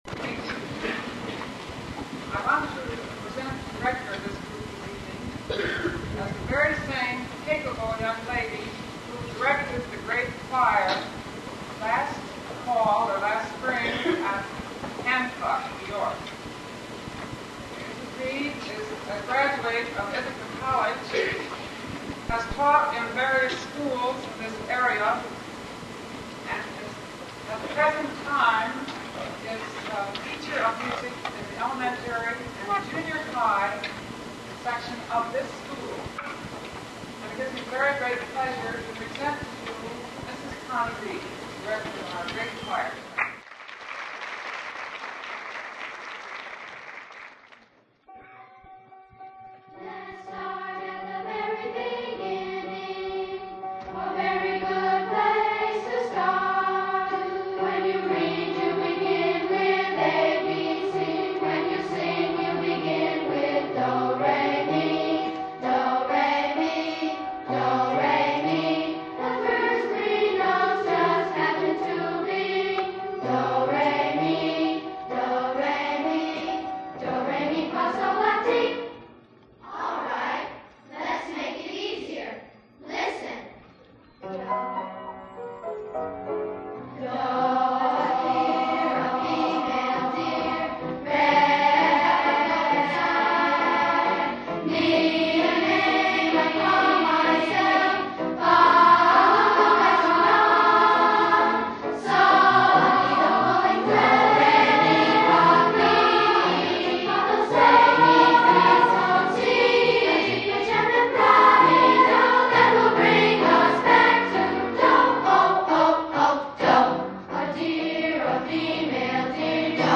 SKCS Alumni Association - School Concerts SKCS Alumni SKCS Concerts skcs1966spring Grade choir SKCS - 1966 - spring SKCS - 1969 - spring SKCS - 1971 - fall SKCS - 1975 - christmas SKCS - 1977 - spring
skcs1966spring-grade_choir.mp3